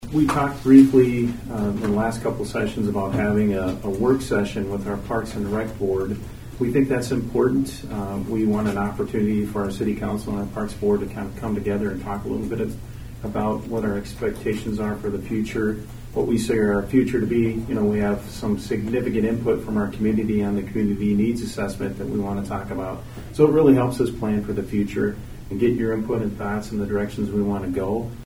Aberdeen City Manager Dave McNeil: